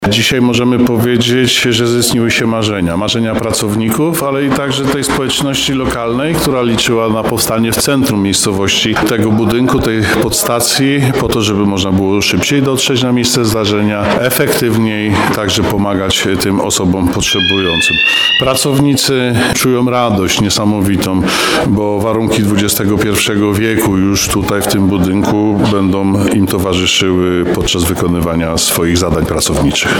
podczas otwarcia nowej podstacji pogotowia w Borowej